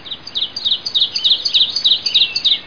Songbird.mp3